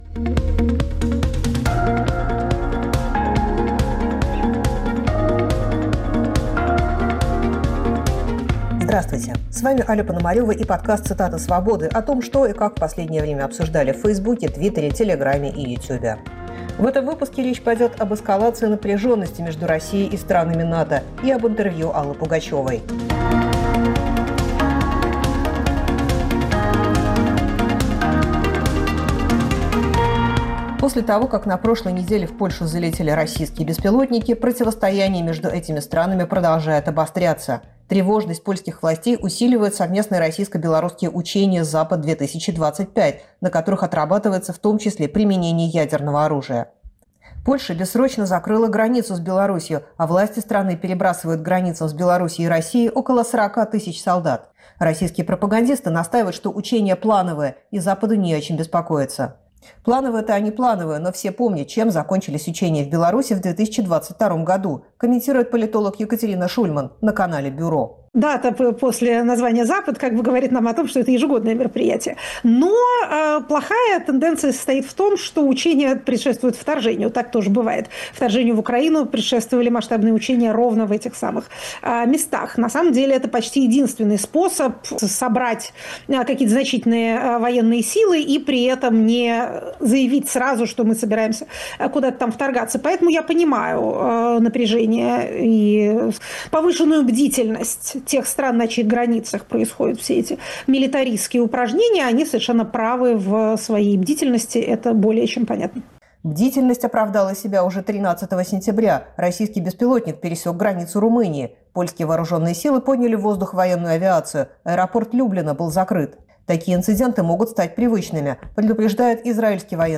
Россия провоцирует НАТО. Интервью Аллы Пугачевой